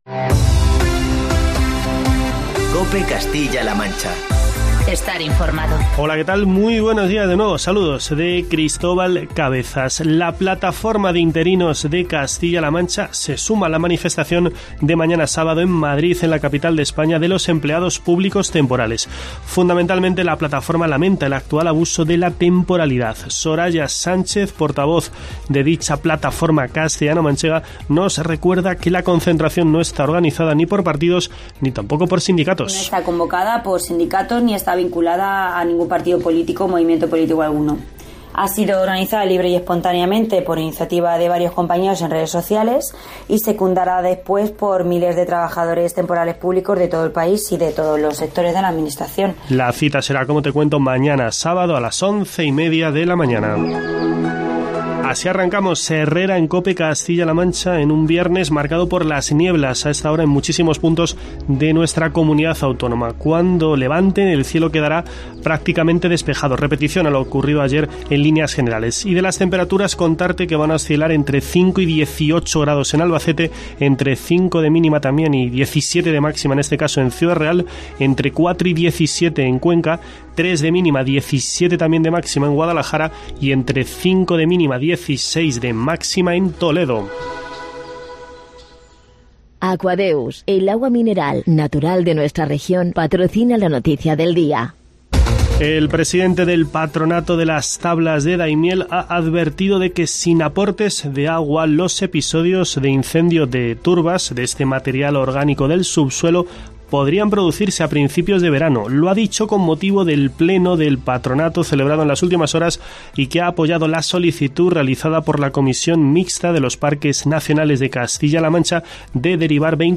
Escucha en la parte superior de esta noticia todos los detalles de esta cuestión en el informativo matinal de COPE Castilla-La Mancha.